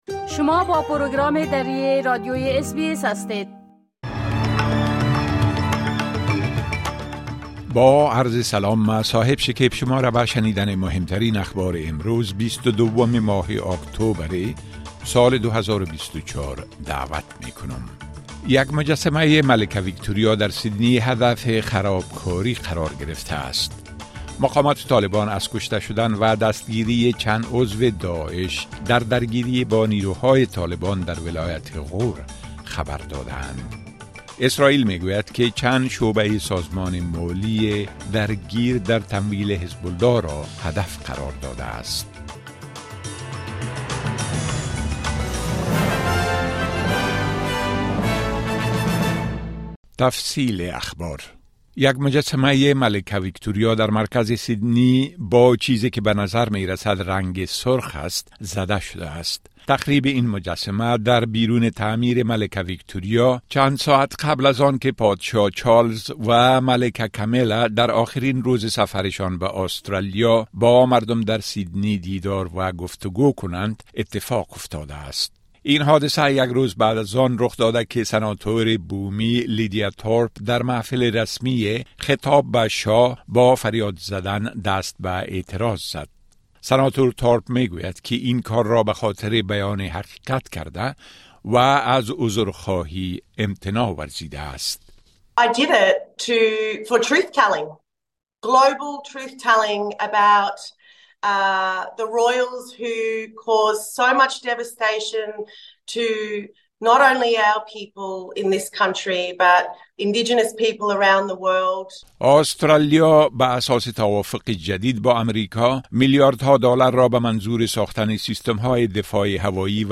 اخبار مهم امروز ۲۲ اكتوبر ۲۰۲۴ به زبان درى از اس بى اس را در اين‌جا شنيده مى‌توانيد.